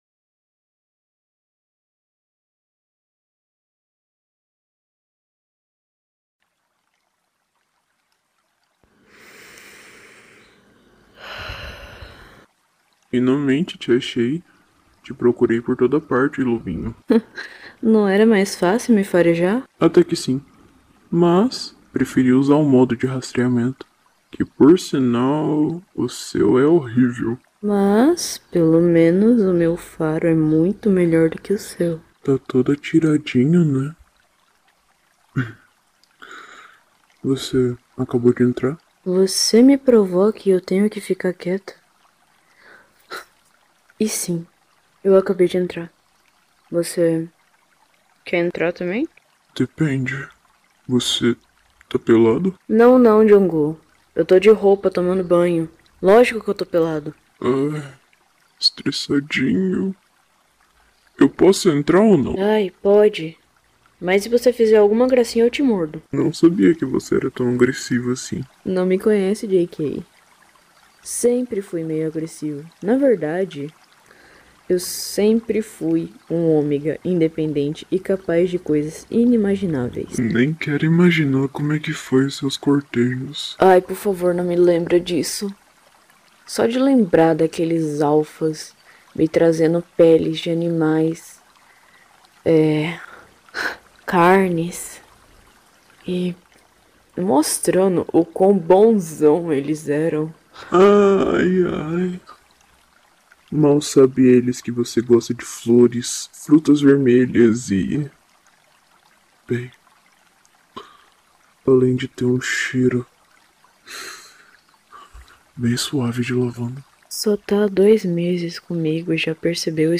asmr jikook "Hibridos" part sound effects free download